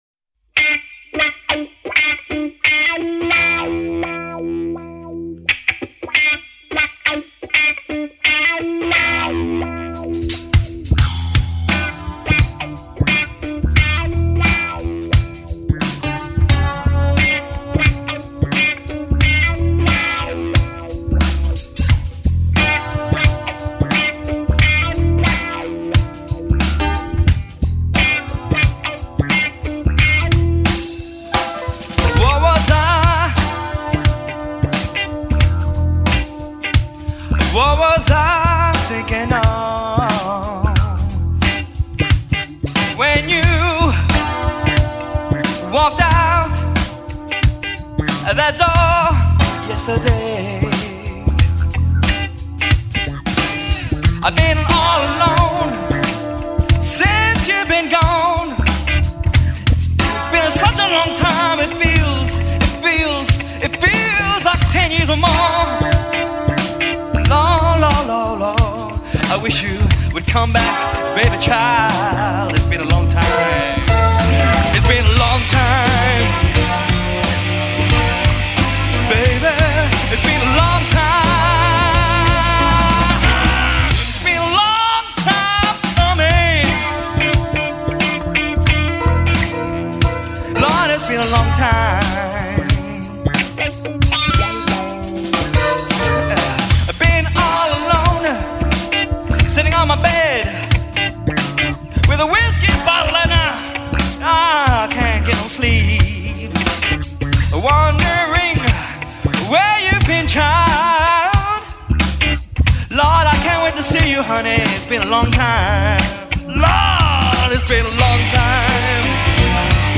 singer and guitarist
Drums
Bass
Keyboards